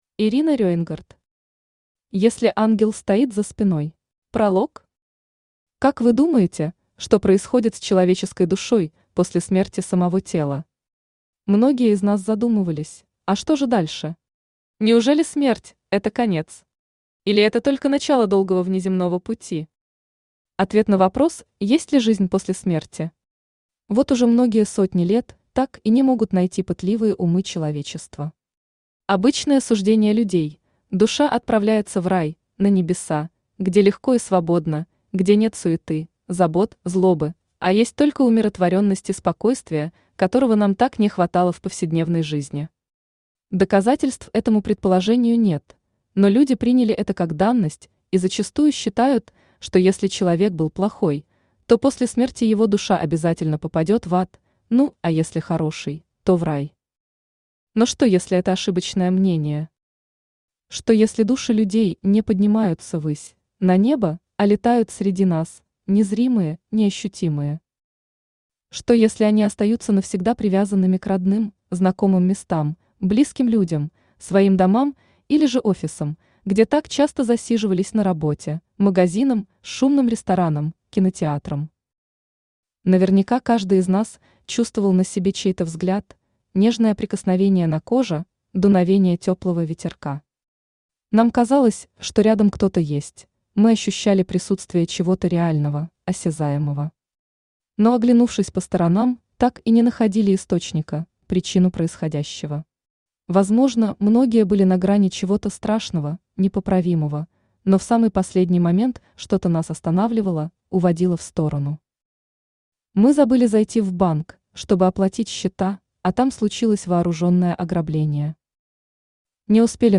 Аудиокнига Если ангел стоит за спиной | Библиотека аудиокниг
Aудиокнига Если ангел стоит за спиной Автор Ирина Рейнгард Читает аудиокнигу Авточтец ЛитРес.